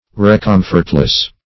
Recomfortless \Re*com"fort*less\